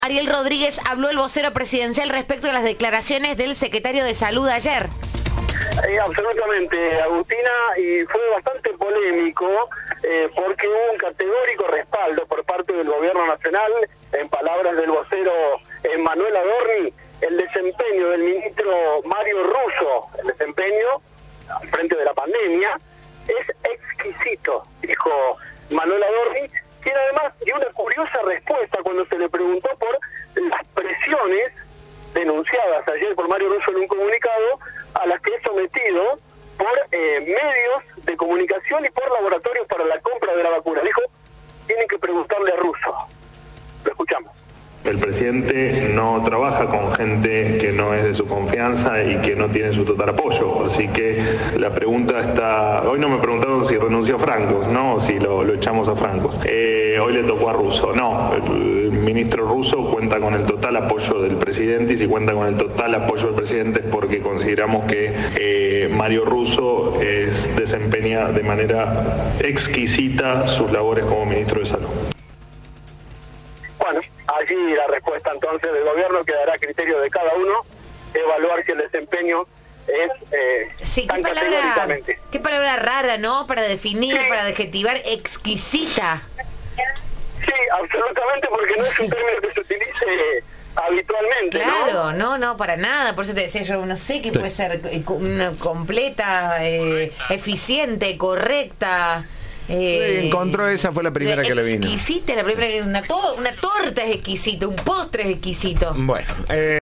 "Milei no trabaja con gente que no sea de su confianza", señaló en conferencia de prensa.
Informe